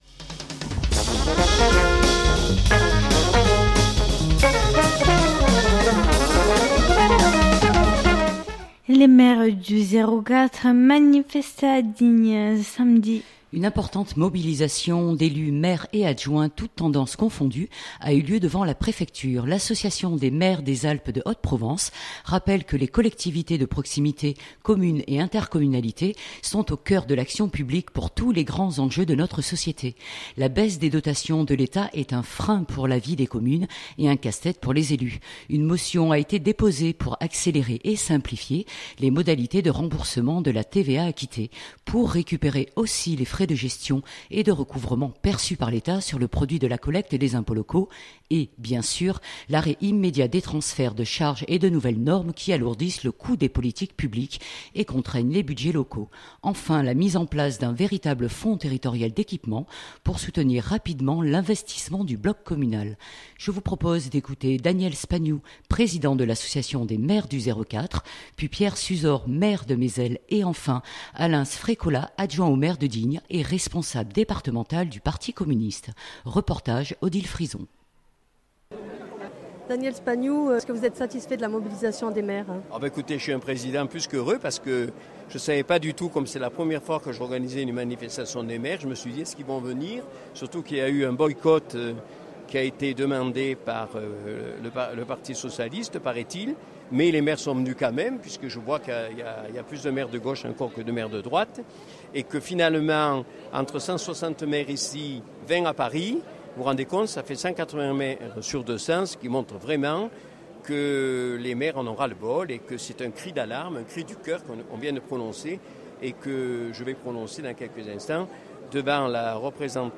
Une importante mobilisation d’élus (maires et adjoints) toutes tendances confondues a eu lieu devant la préfecture.
Je vous propose d’écouter Daniel Spagnou Président de l’association des maires du 04, puis Pierre Suzor maire de Mézel et enfin Alain Sfrécola adjoint au maire de Digne, et responsable départemental du parti communiste.